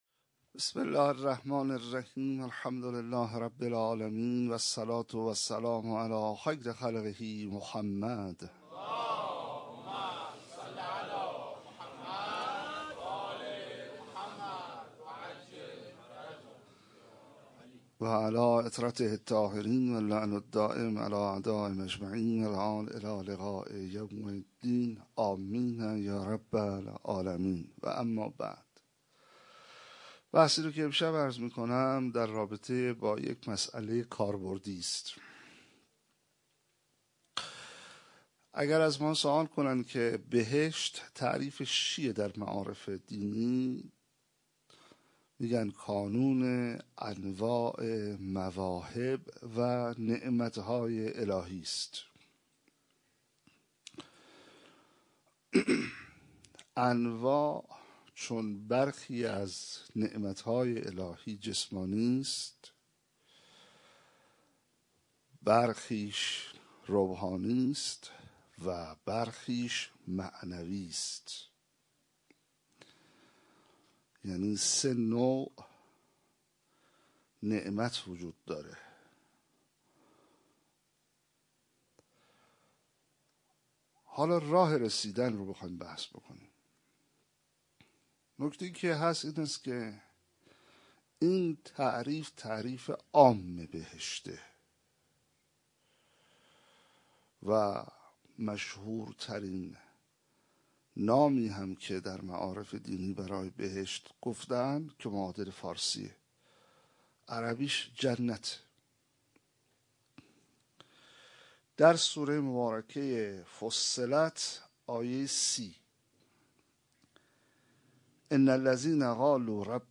12 فروردین 97 - مسجد امیر - سخنرانی